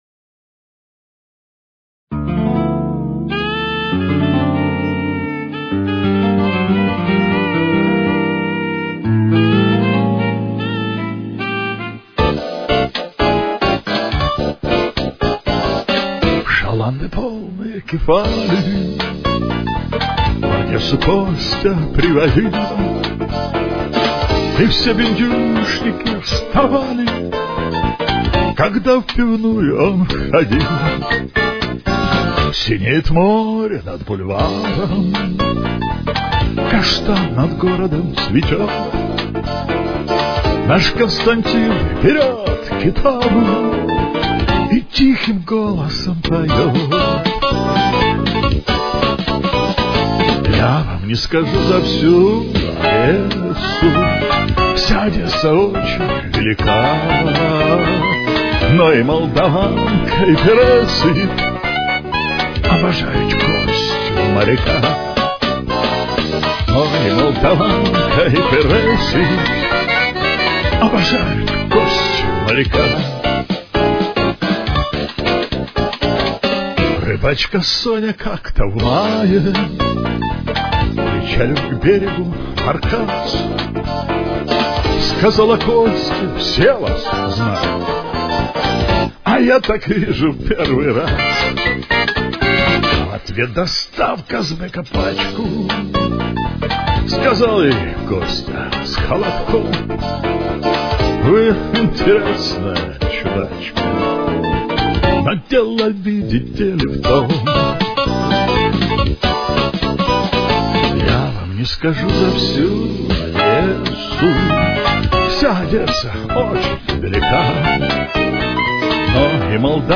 с очень низким качеством (16 – 32 кБит/с)
Темп: 122.